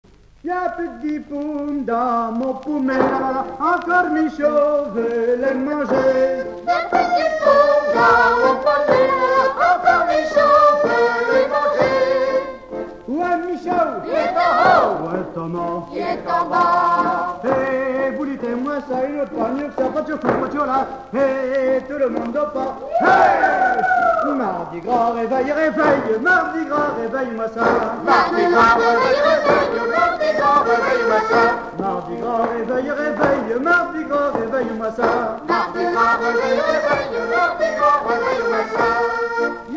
Bois-de-Céné
danse : ronde : grand'danse
Genre énumérative
Catégorie Pièce musicale inédite